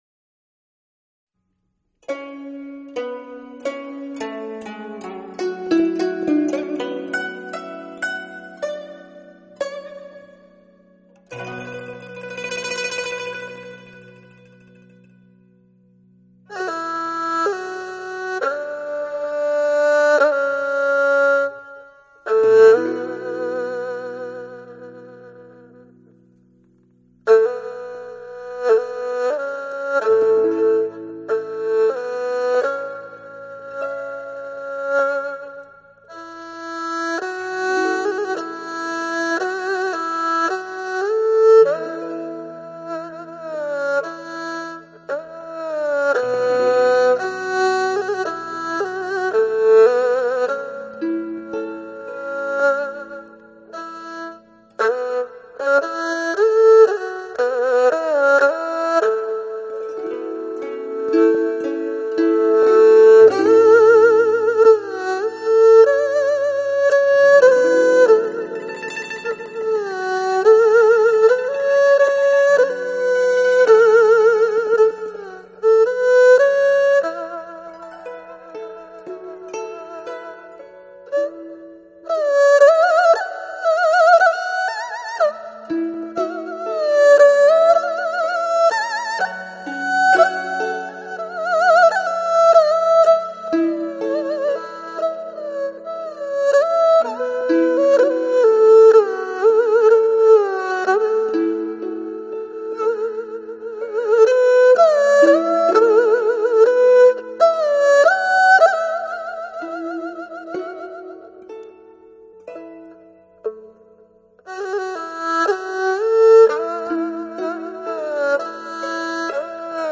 Tradícionális kínai zene